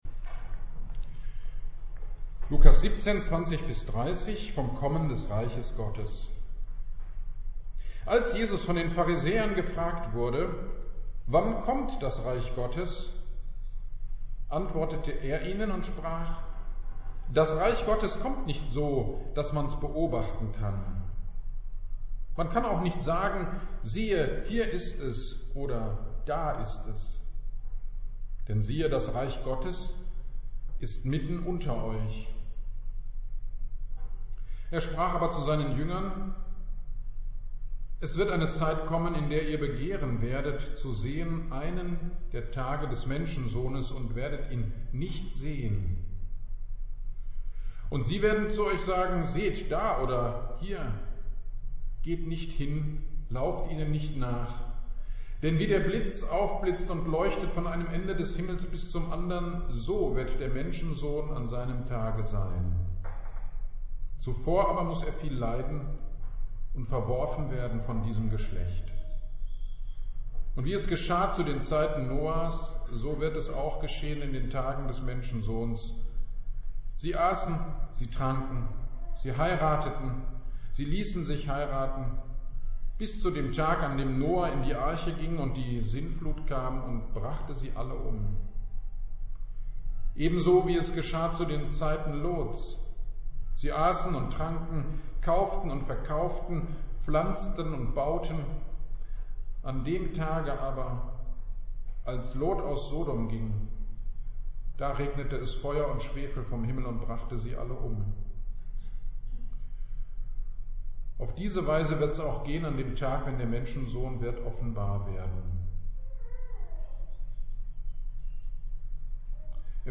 GD am 06.11.22 Predigt zu Lukas 17,20-23